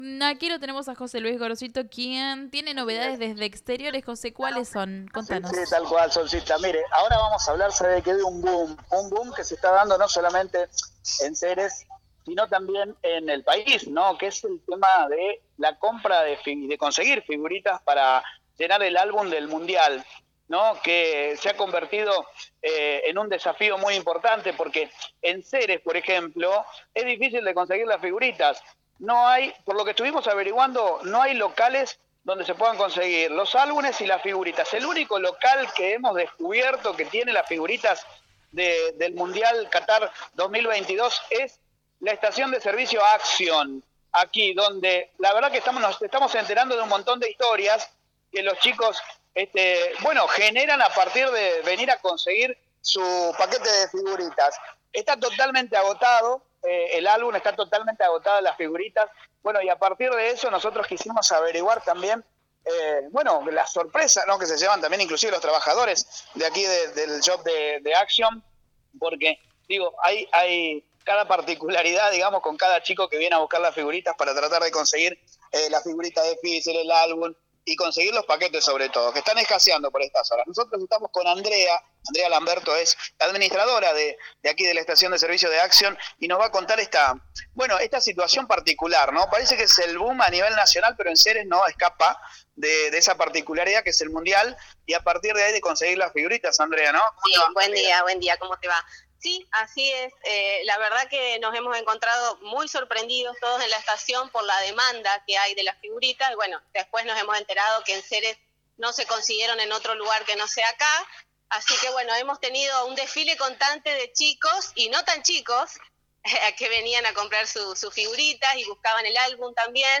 Escucha el móvil en vivo que salió esta mañana desde el shop de la Estación de Servicio.